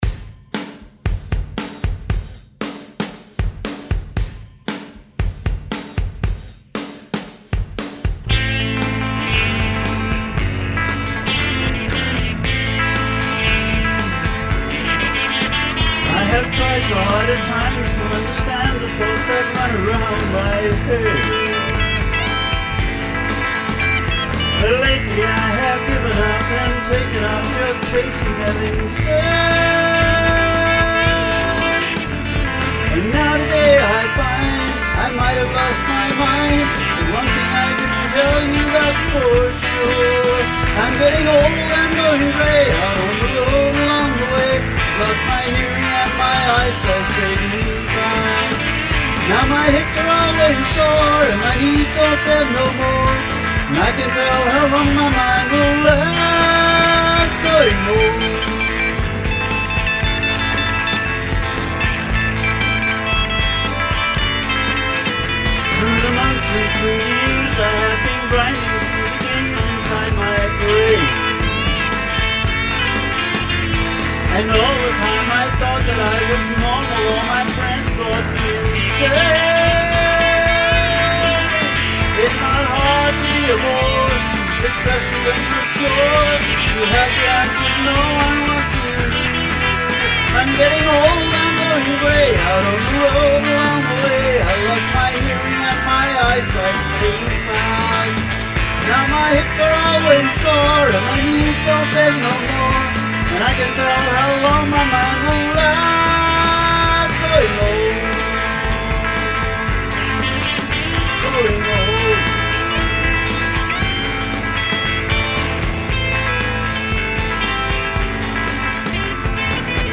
This is a wild version and I don’t play it quite this fast but you should get the idea.